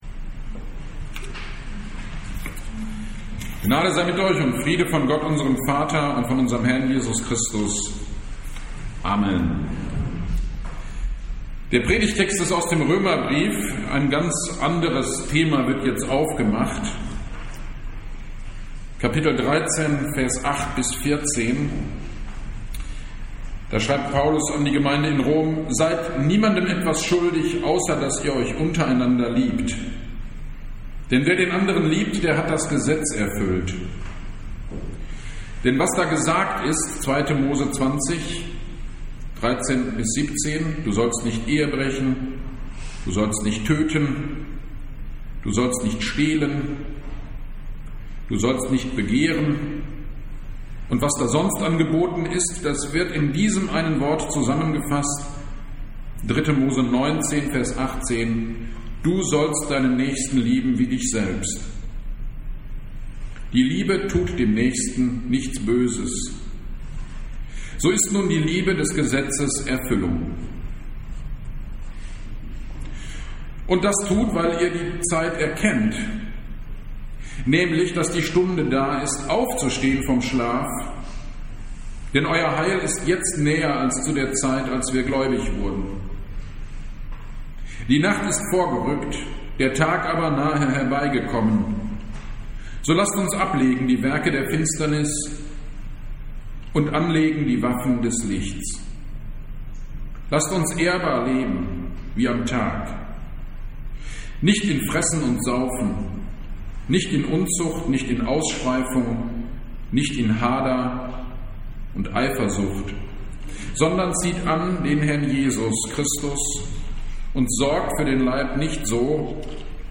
4. Advent 2021 – Predigt zu Römer 13.8-14